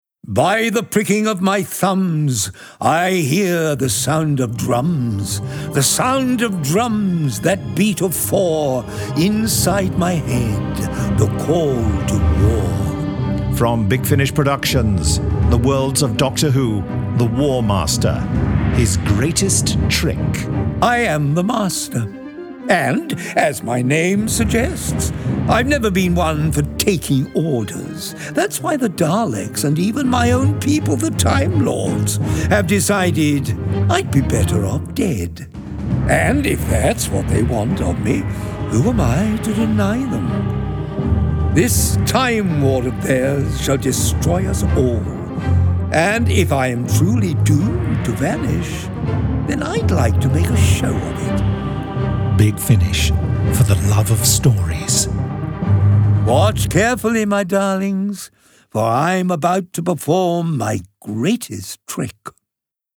Starring Derek Jacobi